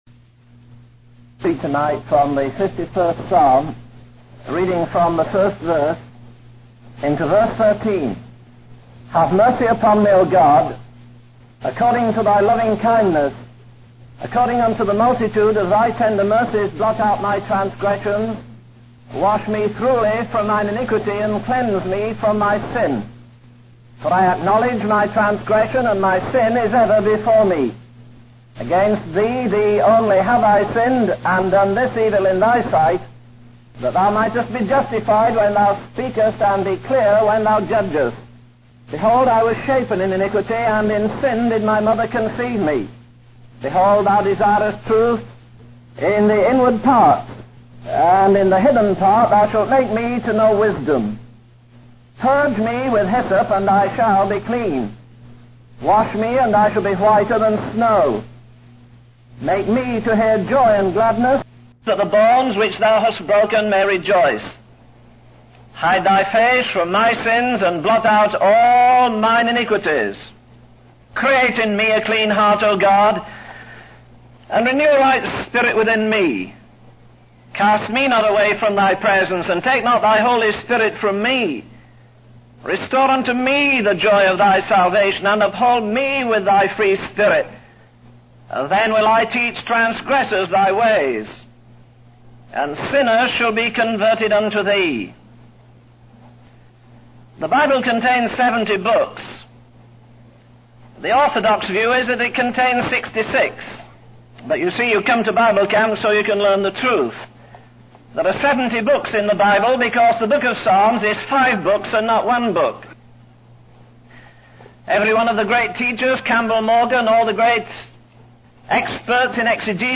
In this sermon, the speaker reflects on the transformative power of God's mercy and grace.